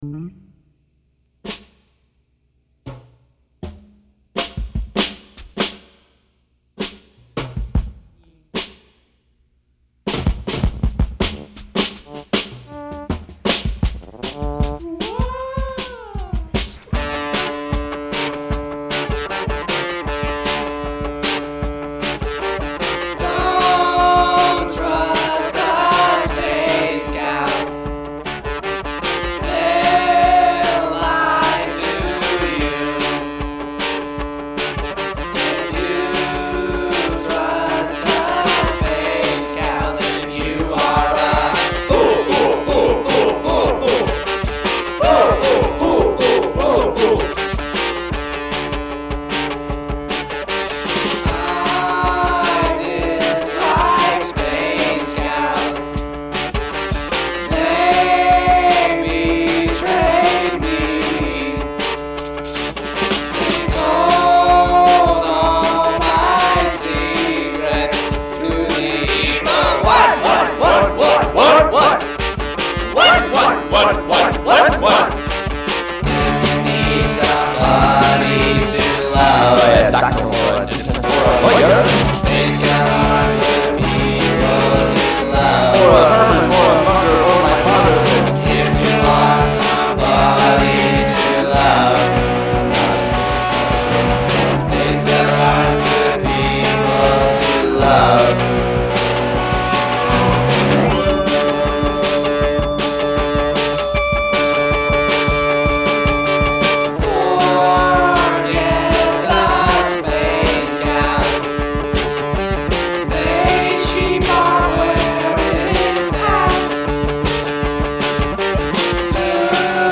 I think I did the organ in the middle but I’m not sure.